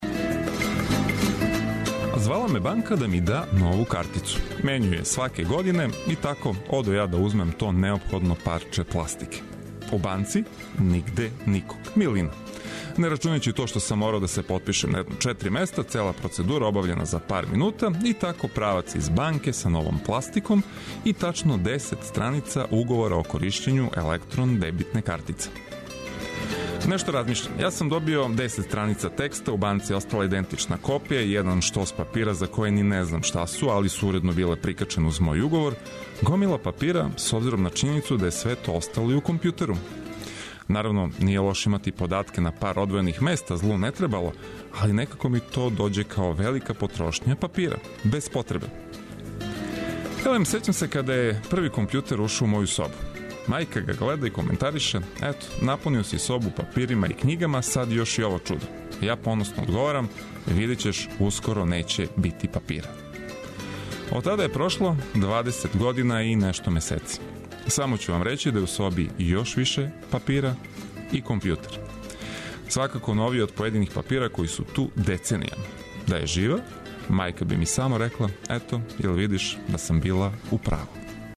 И за једне и за друге овог јутра ћемо вам понудити добру музику и неке интересантне приче, као и најнужније информације!